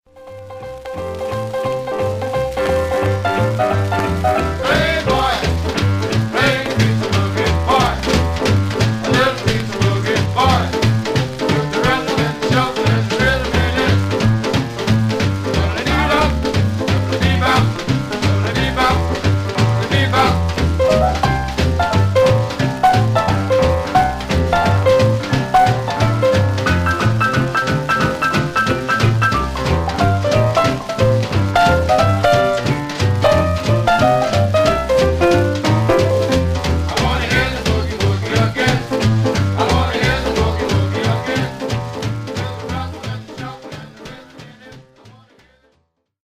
Some surface noise/wear
Mono
R&B Instrumental Condition